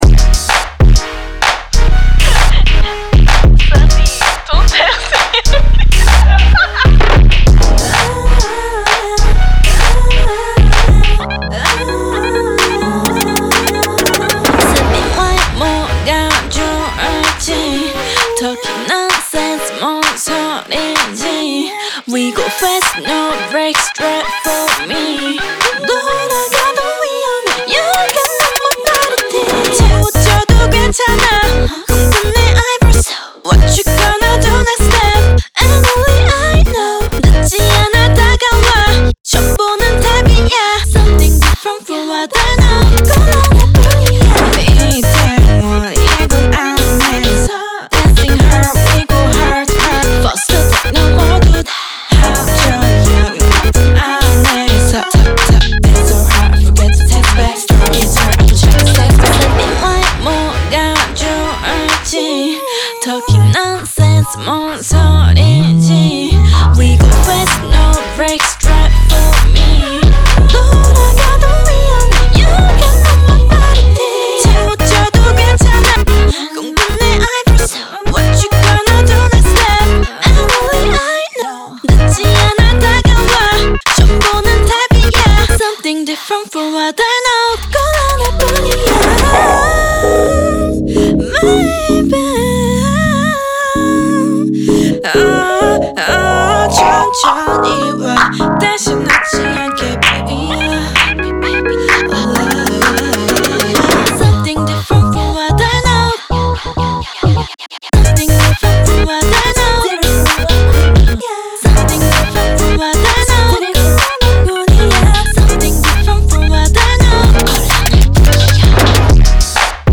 BPM129-129
Audio QualityPerfect (High Quality)
K-Pop song for StepMania, ITGmania, Project Outfox
Full Length Song (not arcade length cut)